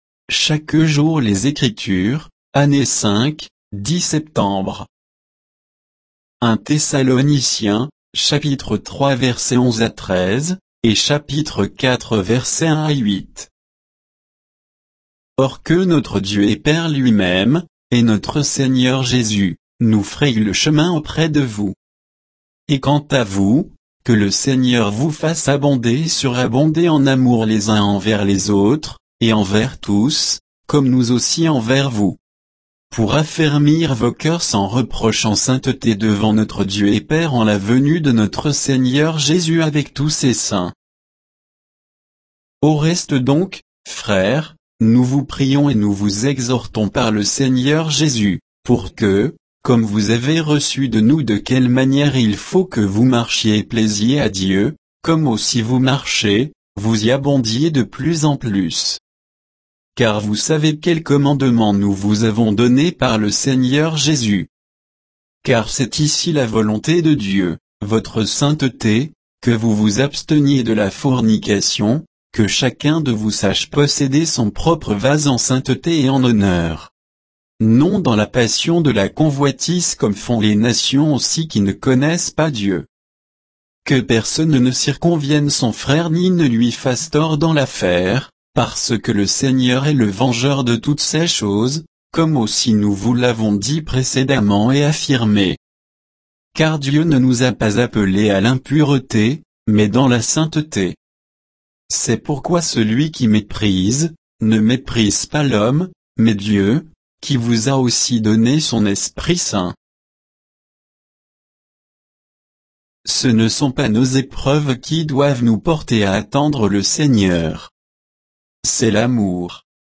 Méditation quoditienne de Chaque jour les Écritures sur 1 Thessaloniciens 3, 11 à 4, 8